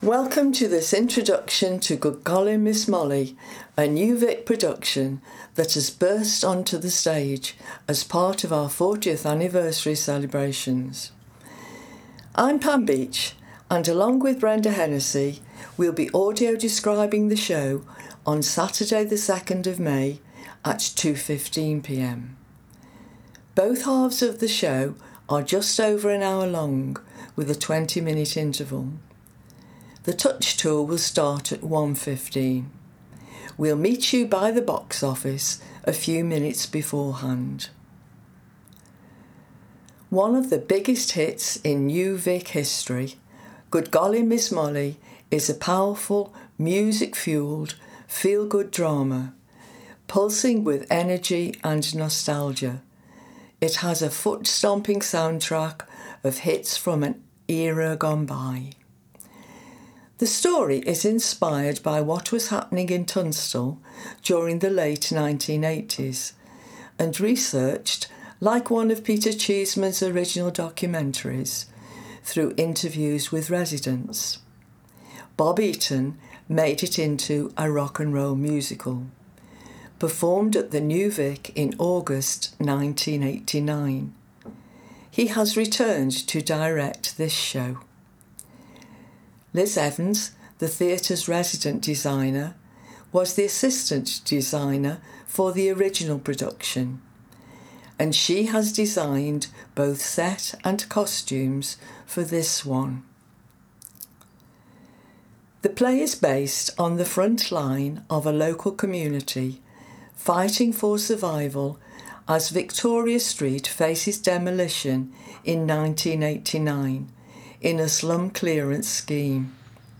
As part of the audio described service, A free audio introduction will be emailed to you in advance of the performance, which gives details about the production including costumes, sets and individual characters. Good Golly Miss Molly Saturday 2 May 2.15pm You can listen to the Audio Described introduction for Good Golly Miss Molly here